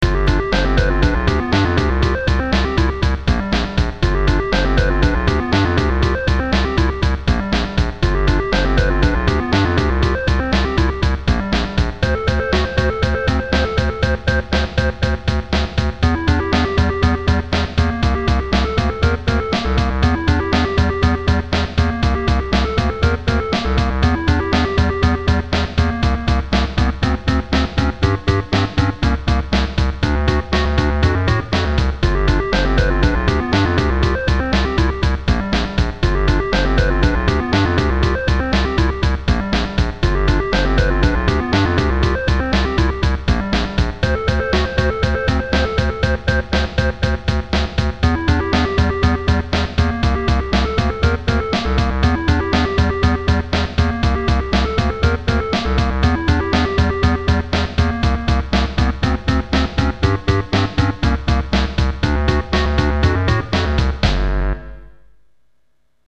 Yes, it's a cover-tune.